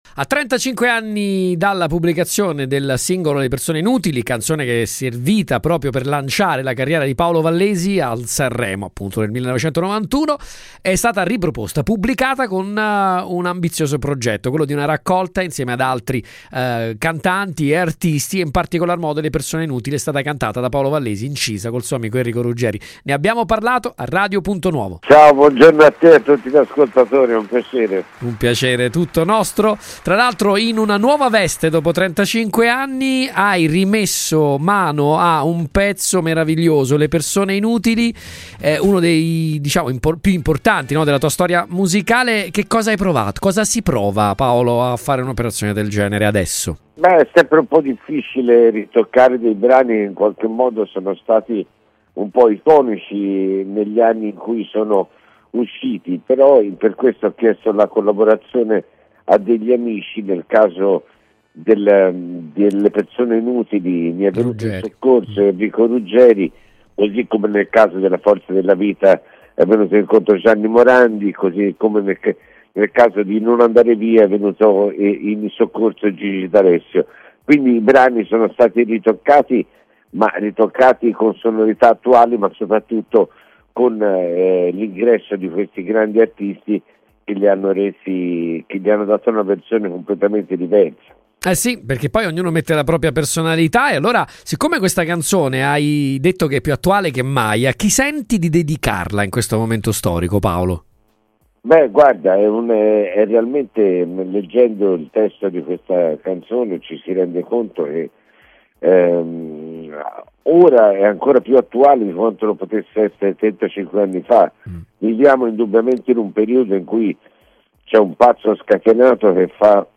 podcast-intervista-vallesi.mp3